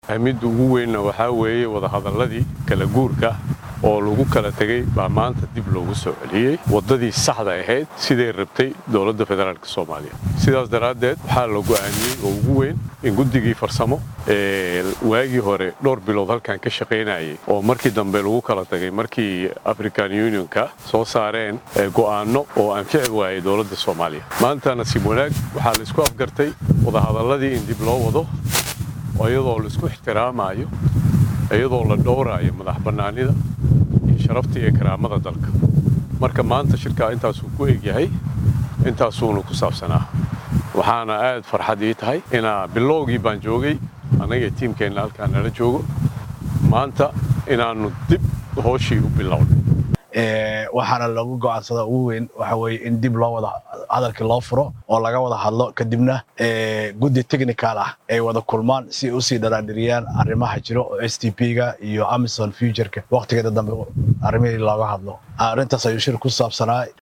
Markii shirkaan la soo gabagabeeyay ayaa waxaa si wada jir ah warbaahinta ula hadlay agaasimeyaasha guud ee wasaaradaha amniga iyo gaashaandhiga dowlada Fedraalka Soomaaliya ayagoo faahfaahin ka bixiyay waxyaabaha ugu muhiimsan ee kalankaan looga hadlay.